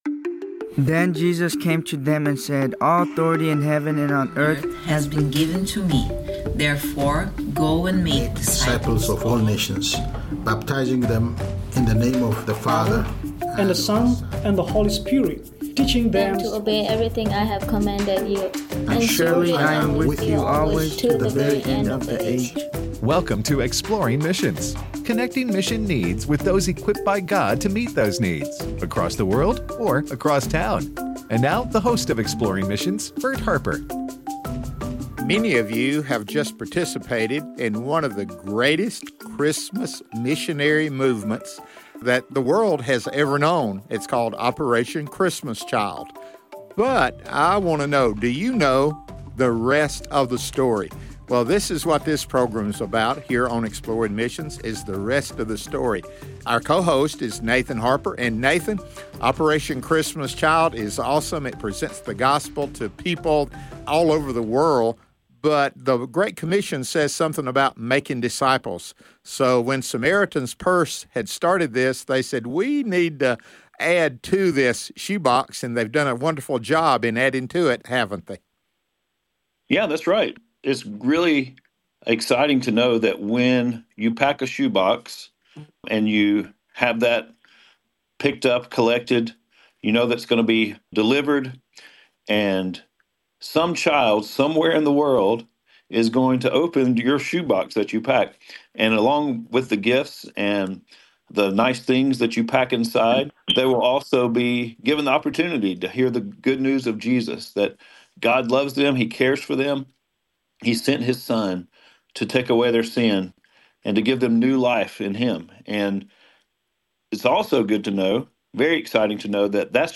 Discipleship Through The Greatest Journey: A Conversation